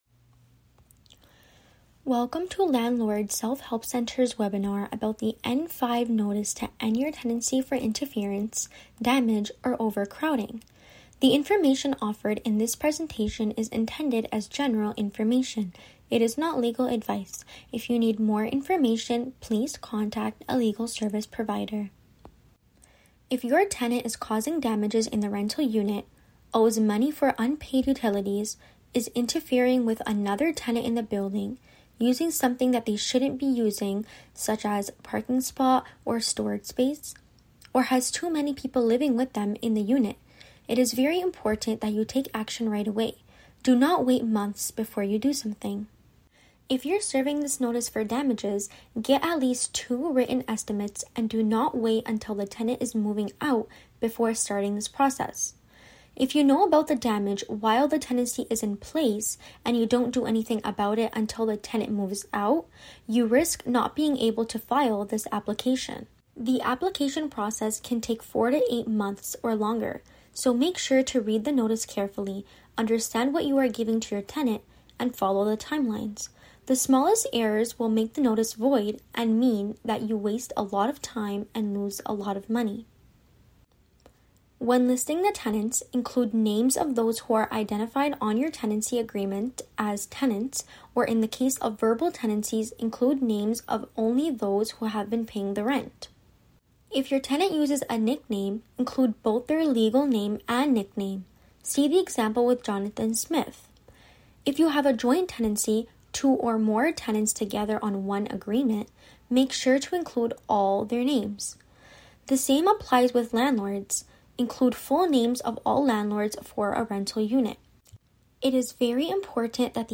N5 – Notice to End your Tenancy for Interfering with Others, Damage or Overcrowding Transcript Introduction Welcome to Landlord’s Self-Help Centre’s webinar about the N5 notice to end your tenancy for interference, damage or overcrowding. The information offered in this presentation is intended as general information, it is not legal advice.